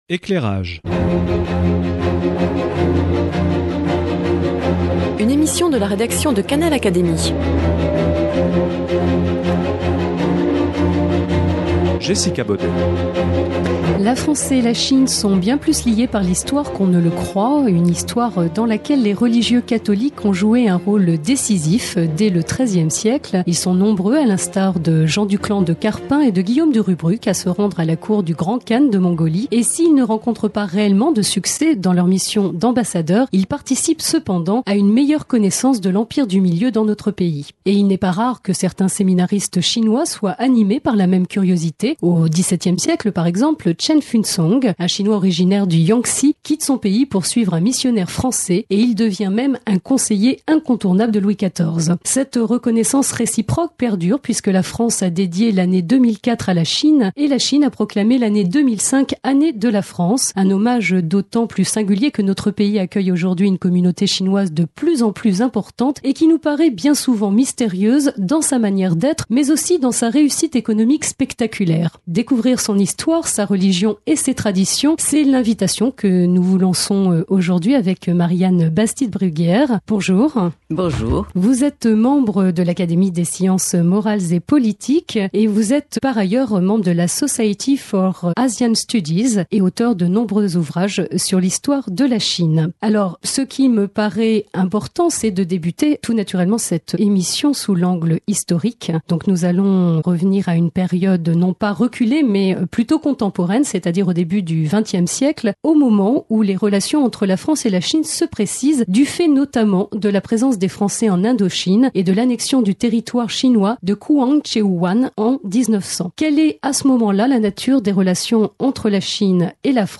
Explications de Marianne Bastid-Bruguière, académicienne et spécialiste de la Chine. Elle nous décrypte cette communauté à travers son histoire et ses traditions avec la France: l'arrivée des premiers étudiants chinois en France au début du siècle, le développement de la communauté. Autant du sujets abordés en compagnie de d'une sinologue renommée.